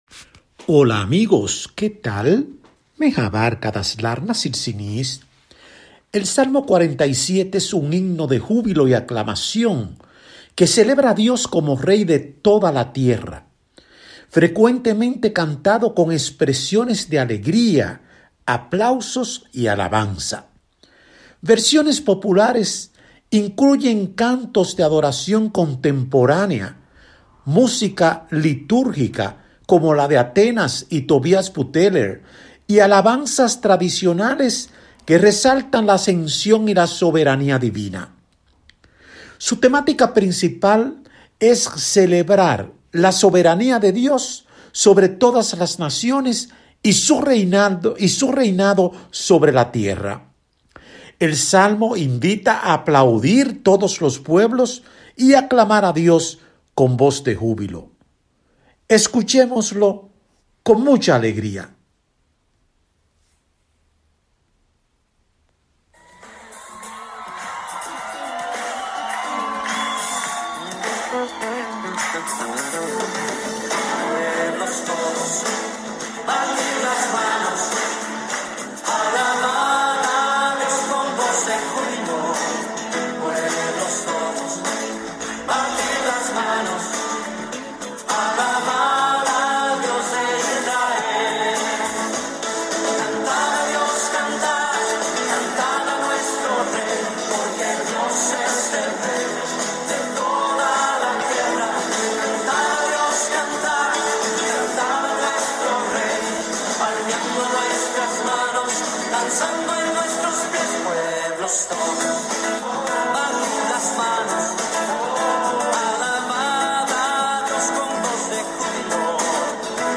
REFLEXIONESEl Salmo 47 es un himno de júbilo y aclamación que celebra a Dios como Rey de toda la tierra, frecuentemente cantado con expresiones de alegría, aplausos y alabanza.